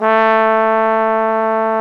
Index of /90_sSampleCDs/Roland L-CDX-03 Disk 2/BRS_Trombone/BRS_Tenor Bone 1